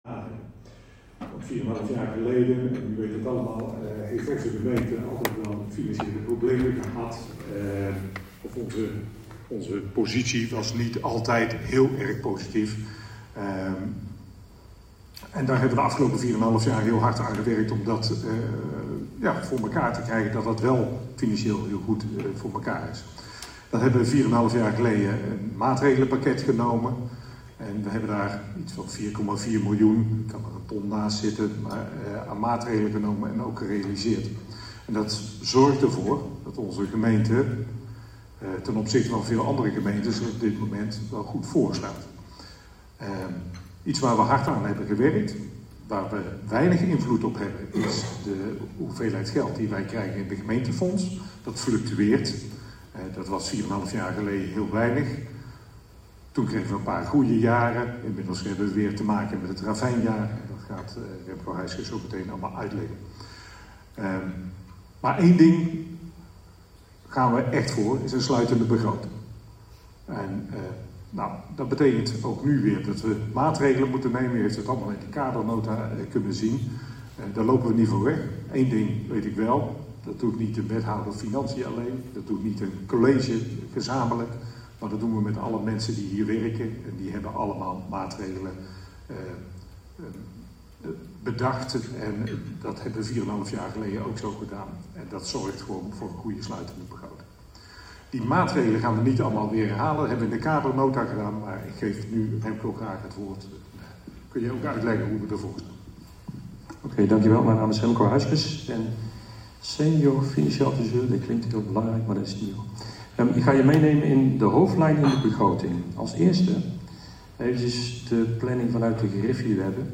Agenda MijnGemeenteDichtbij - Informatie-/uitwisselingsbijeenkomst raad Boxtel dinsdag 30 september 2025 19:30 - 21:30 - iBabs Publieksportaal
Locatie Raadzaal Boxtel